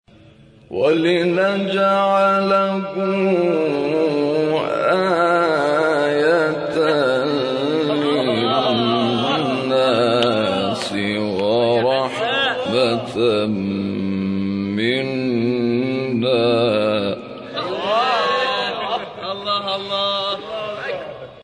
گروه شبکه اجتماعی: مقاطعی از تلاوت قاریان ممتاز و بین‌المللی کشور که به‌تازگی در شبکه اجتماعی تلگرام منتشر شده است، می‌شنوید.